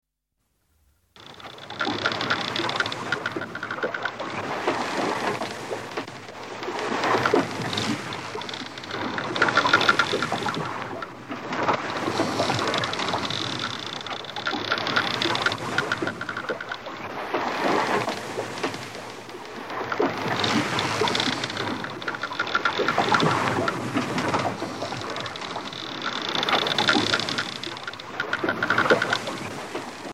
دانلود آهنگ سه بعدی 1 از افکت صوتی طبیعت و محیط
جلوه های صوتی